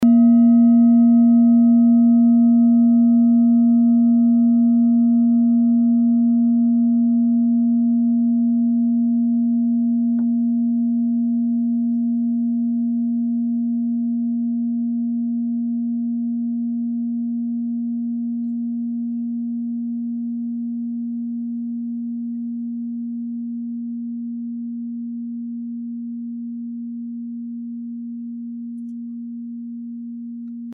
Klangschale Bengalen Nr.3
Klangschale-Gewicht: 720g
Sie ist neu und wurde gezielt nach altem 7-Metalle-Rezept in Handarbeit gezogen und gehämmert.
(Ermittelt mit dem Filzklöppel oder Gummikernschlegel)